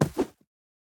Minecraft Version Minecraft Version 1.21.4 Latest Release | Latest Snapshot 1.21.4 / assets / minecraft / sounds / mob / armadillo / roll4.ogg Compare With Compare With Latest Release | Latest Snapshot
roll4.ogg